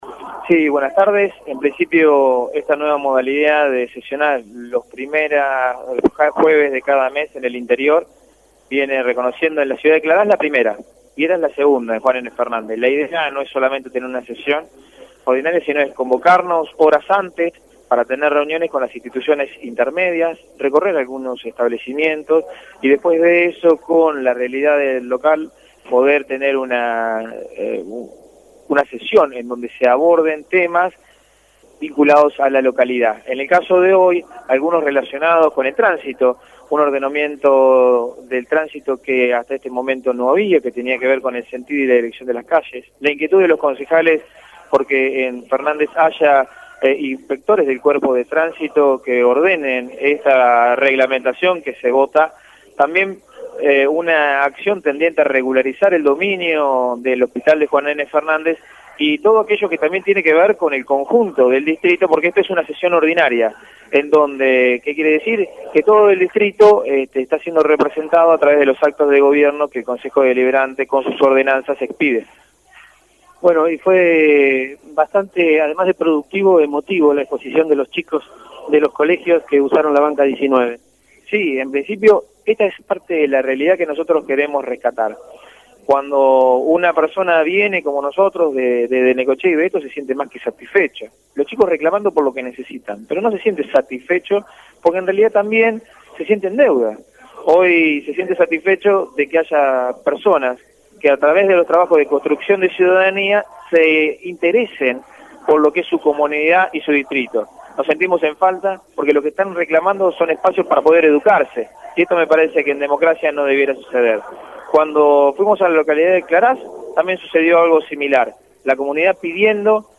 Al finalizar la sesión, el Presidente del HCD, Dr. Fernando Kuhn, en exclusiva para LU 24 y JNFNet, únicos medios presentes, se mostró agradecido con el pueblo de Juan N. Fernández por la participación y destacó los proyectos que fueron aprobados por unanimidad.
Escuchar las declaraciones Dr. Fernando Kuhn